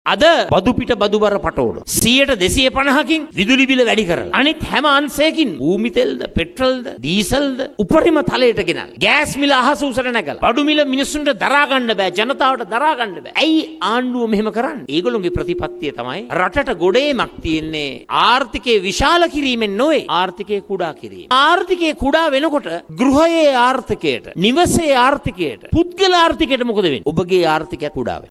දිනෙන් දින ඉහළ යන විදුලි බිල මෙන්ම බඩු මිල හමුවේ ජනතාව දරිද්‍රතාවයට පත් ව ඇති බවටයි සජිත් ප්‍රේමදාස මහතා බිබිල – මැදගම ප්‍රදේශයේදී පැවති ජනහමුවකට එක් වෙමින් කියා සිටියේ.